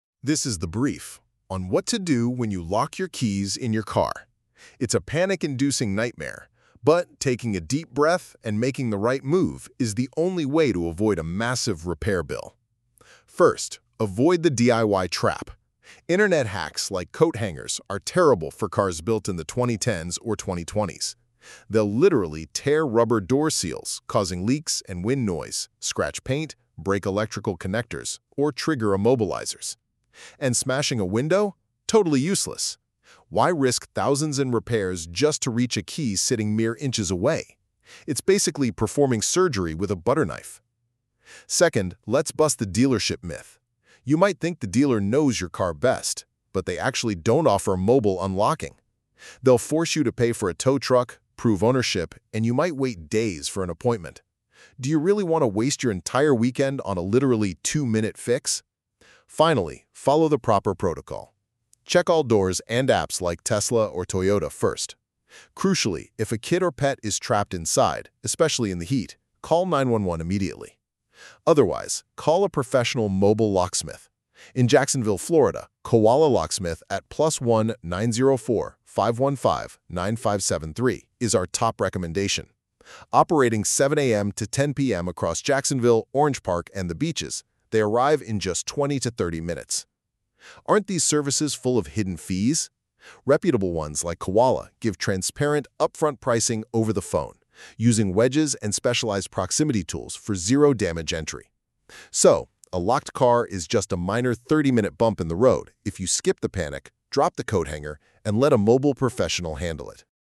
Short narrated guides on car key scams, emergency lockouts, luxury programming, and Florida-specific gotchas.